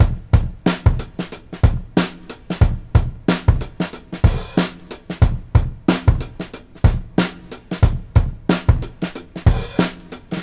• 92 Bpm Drum Loop C Key.wav
Free breakbeat sample - kick tuned to the C note. Loudest frequency: 1056Hz
92-bpm-drum-loop-c-key-flw.wav